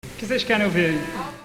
Abaixo algumas frases ditas por ele...